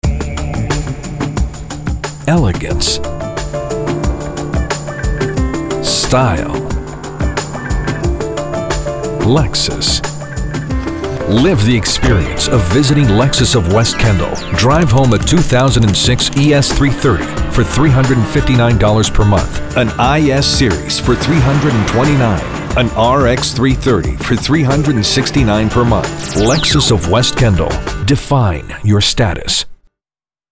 Masculino
Inglês - América do Norte